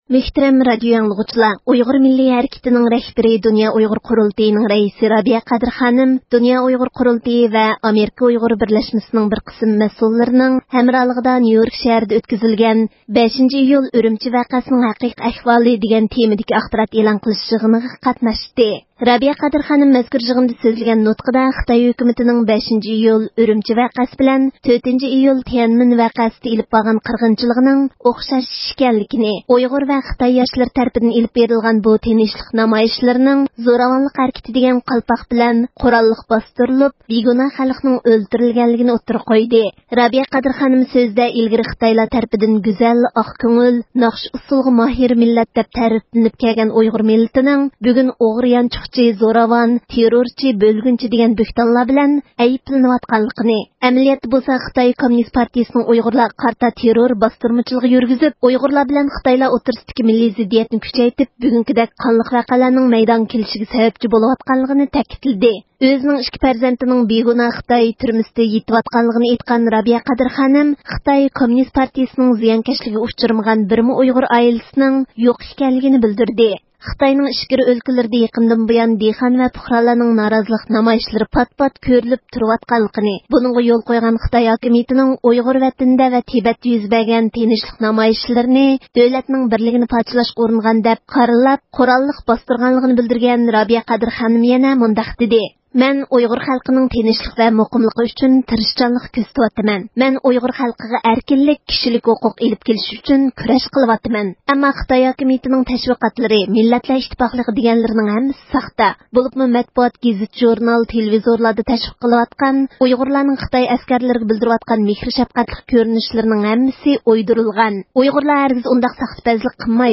رابىيە قادىر خانىم نيۇيوركتىكى مۇخبىرلارنى كۈتىۋىلىش يىغىنىدا نۇتۇق سۆزلىدى – ئۇيغۇر مىللى ھەركىتى
دۇنيا ئۇيغۇر قۇرۇلتىيىنىڭ رەئىسى رابىيە قادىر خانىم 21  – ئىيۇل كۈنى، نيۇيورك شەھىرىدە ئۆتكۈزۈلگەن 5 »  – ئىيۇل ئۈرۈمچى ۋەقەسىنىڭ ھەقىقىي ئەھۋالى» دېگەن تېمىدا ئۆتكۈزۈلگەن ئاخبارات ئېلان قىلىش يىغىنىغا قاتناشتى.
يىغىنغا خىتاي دېموكراتلىرى، تىبەت ۋەكىللىرى، خەلقئارا مەتبۇئات مۇخبىرلىرى شۇنىڭدەك خىتاينىڭ چەتئەللەردە تۇرۇشلۇق مۇخبىرلىرىدىن بولۇپ، 1000 غا يقېىن كىشى قاتناشتى.